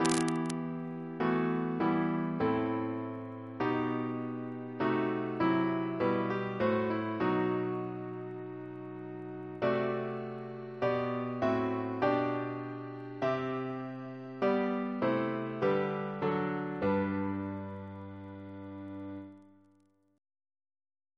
Double chant in G Composer: Samuel Wesley (1766-1837) Note: fauxbourdon of Wesley-G Reference psalters: PP/SNCB: 93